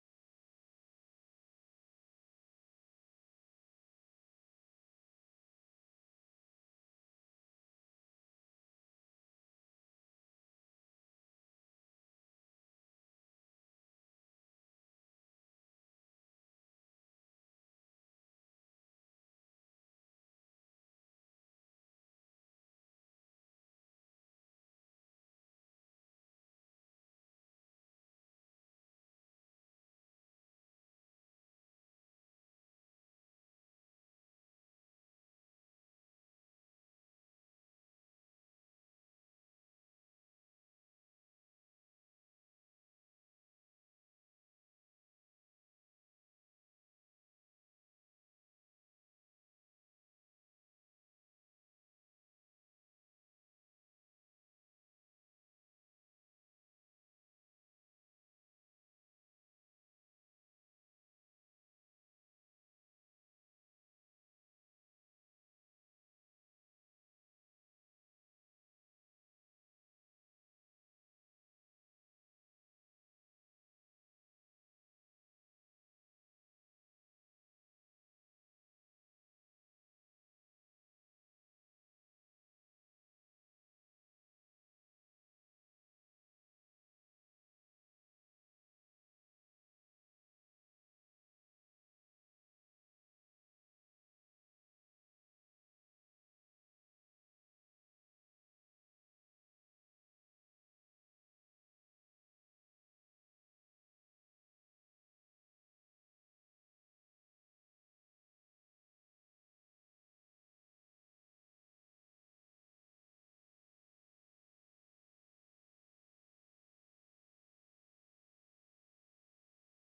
17ª Sessão Ordinária de 2020